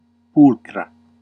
Ääntäminen
Ääntäminen Classical: IPA: /ˈpul.kʰra/ Haettu sana löytyi näillä lähdekielillä: latina Käännöksiä ei löytynyt valitulle kohdekielelle.